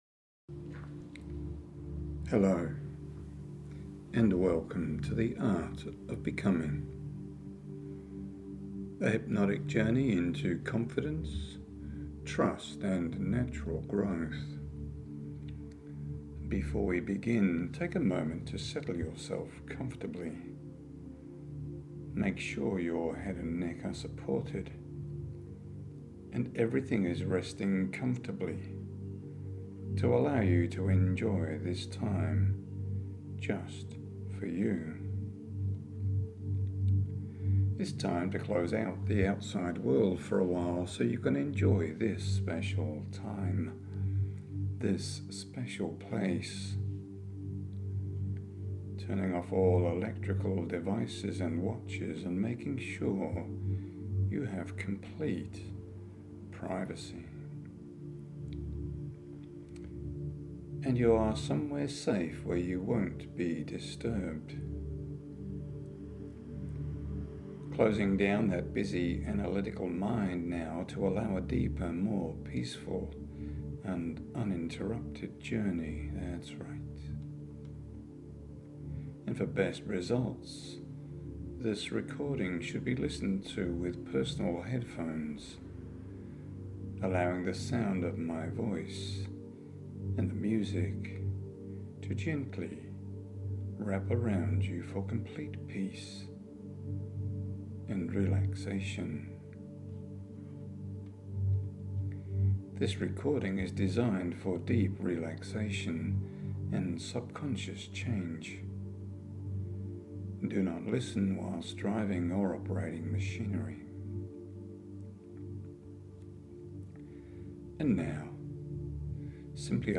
“The Art of Becoming” A Hypnotic Journey into Confidence, Trust and Natural Growth MP3 Audio Stop “waiting” until you feel confident, Stop overthinking it, learn how to trust and evolve the way that life intended.